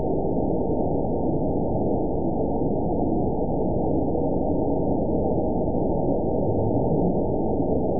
event 922716 date 03/18/25 time 00:08:09 GMT (1 month, 2 weeks ago) score 9.50 location TSS-AB04 detected by nrw target species NRW annotations +NRW Spectrogram: Frequency (kHz) vs. Time (s) audio not available .wav